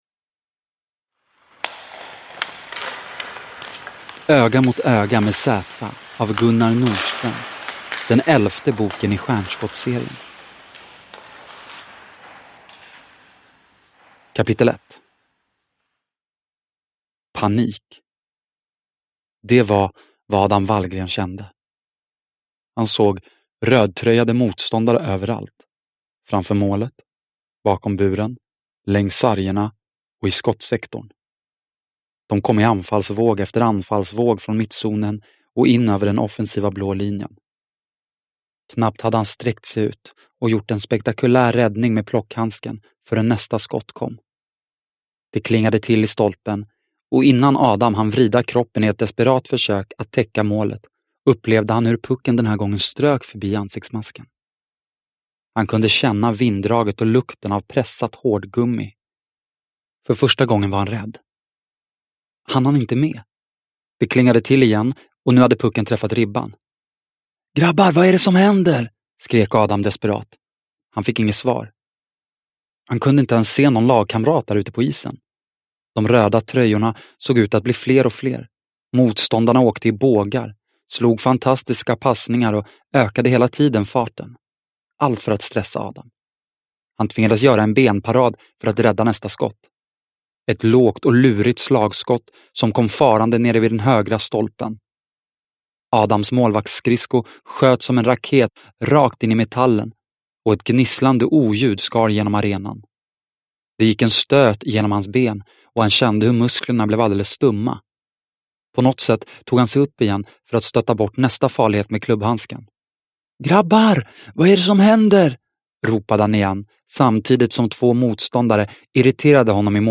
Öga mot öga med Zäta – Ljudbok – Laddas ner